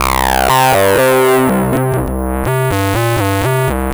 Jungle Dirt C 122.wav